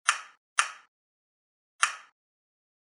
Звуки краба или рака: Щелканье клешни краба (защелкивание)